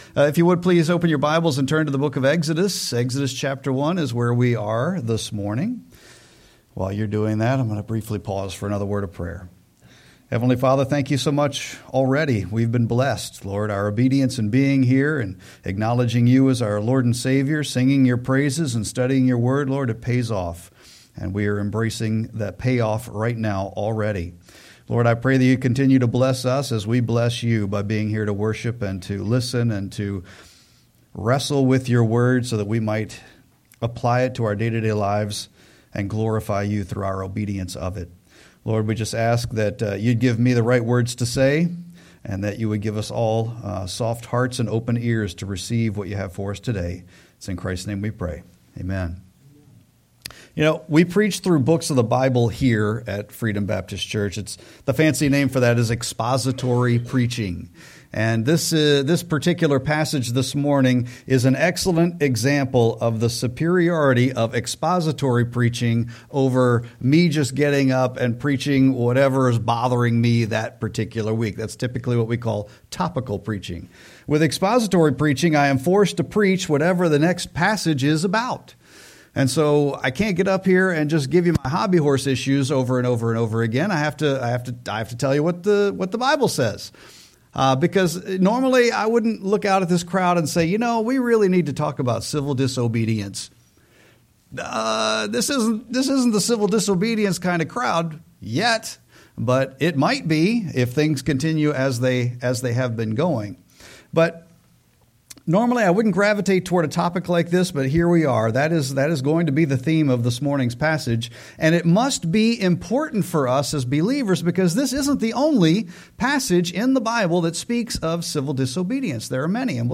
Sermon-1-11-26.mp3